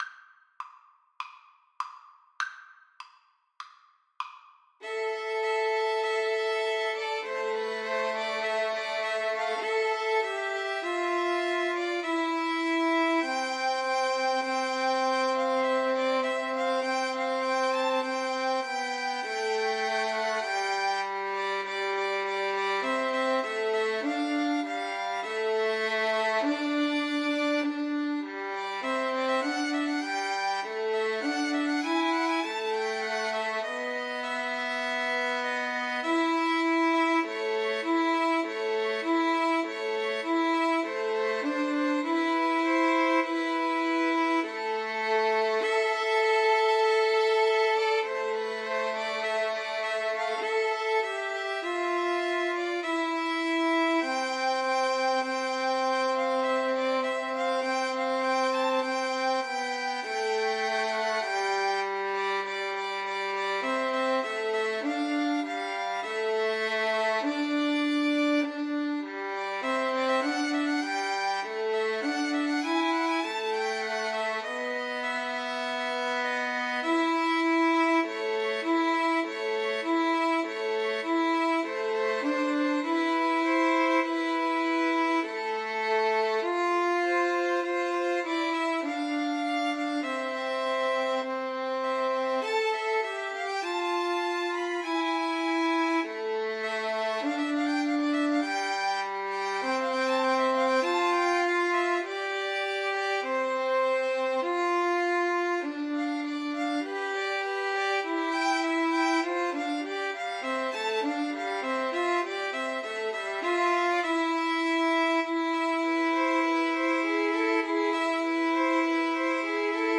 Free Sheet music for Violin Trio
[Moderato]
Classical (View more Classical Violin Trio Music)